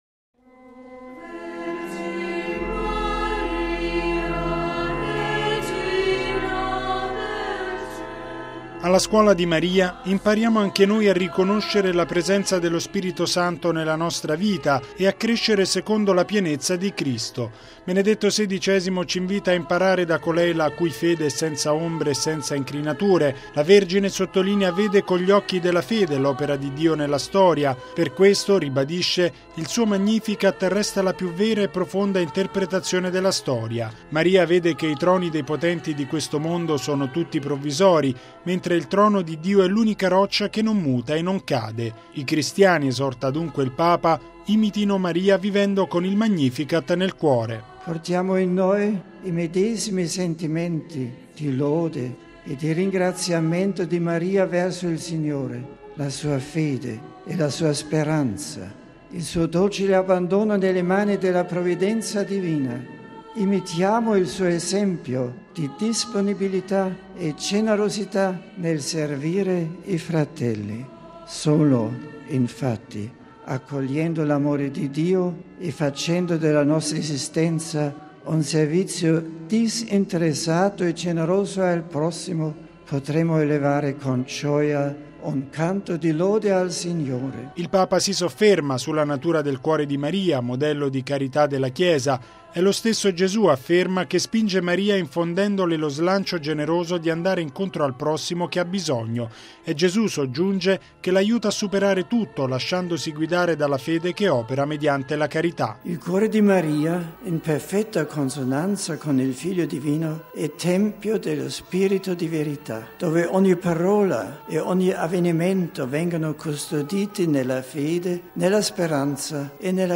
(Canto - Ave Maria di Lourdes)